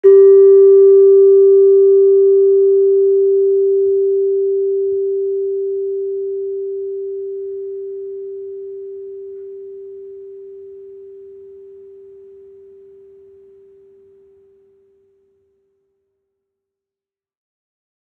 Gender-2-A2-f.wav